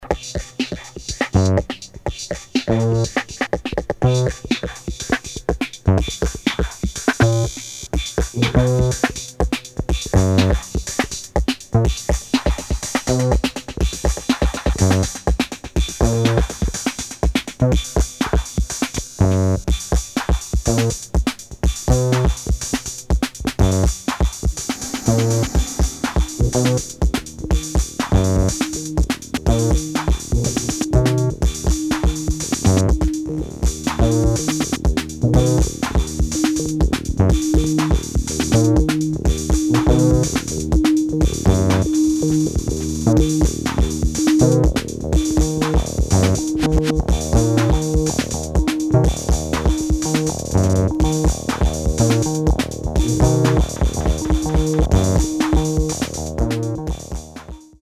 [ BASS / DOWNBAET ]